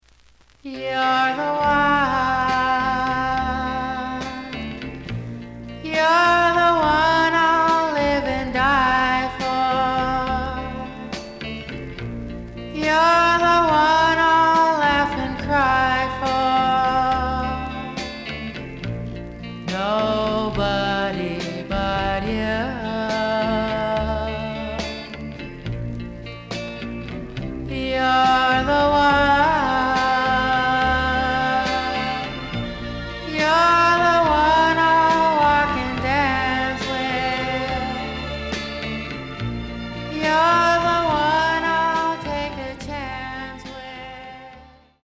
The audio sample is recorded from the actual item.
●Genre: Rhythm And Blues / Rock 'n' Roll
●Record Grading: VG~VG+ (傷はあるが、プレイはおおむね良好。Plays good.)